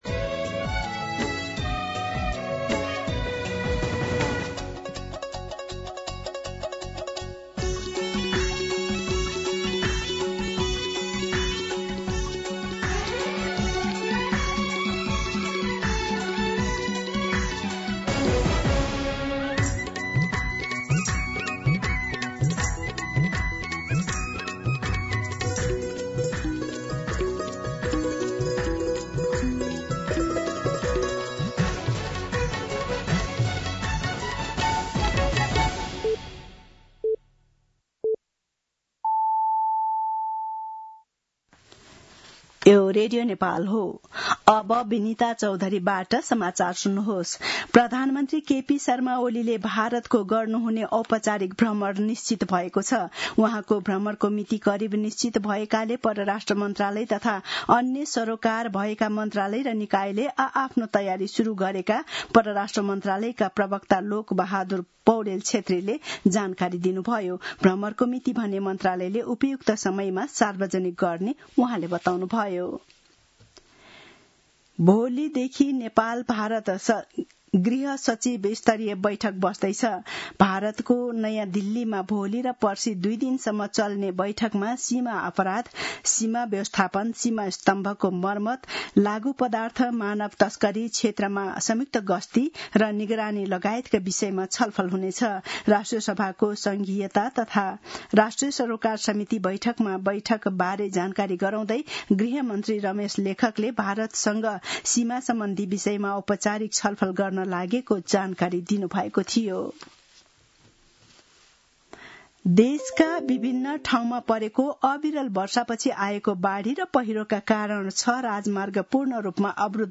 दिउँसो १ बजेको नेपाली समाचार : ५ साउन , २०८२